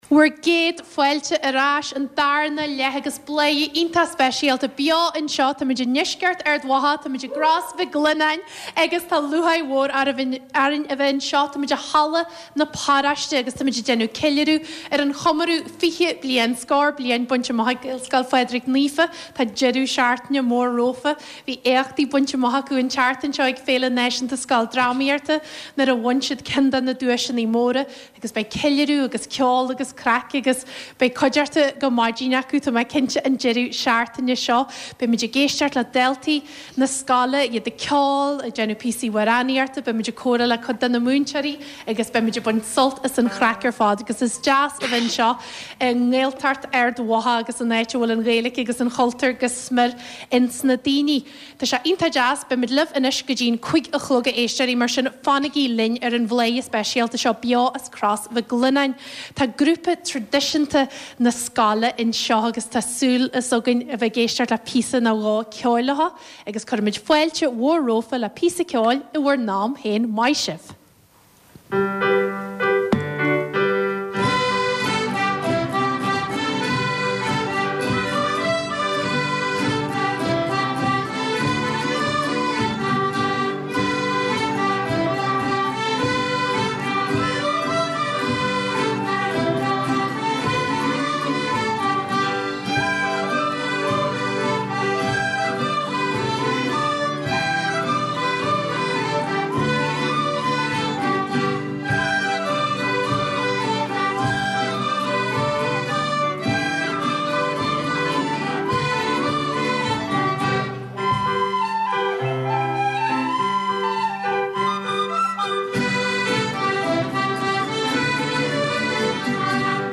Clár siamsaíochta, ceoil agus comhrá a chur i láthair inniu as Ard Mhacha Theas, i mbaile Chrois Mhic Lionnáin áit ar chas muid le go leor de phobal na Gaelscoile ansin agus bunadh na háite fosta.